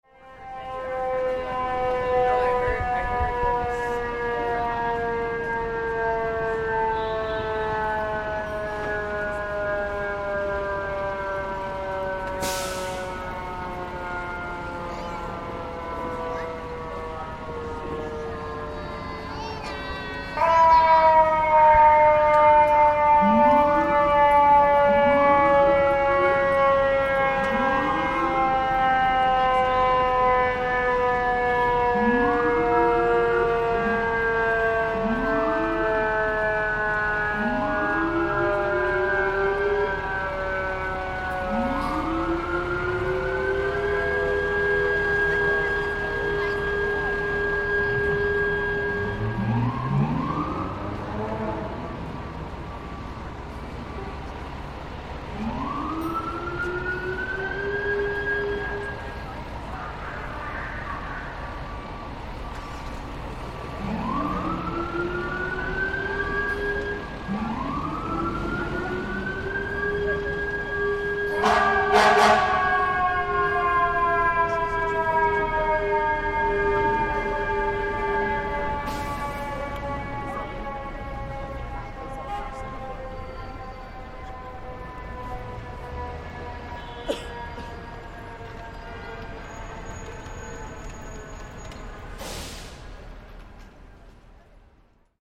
New York City sirens
Sirens! Truly an icon of New York City - here we're tuning into a police car making its way through traffic nearby.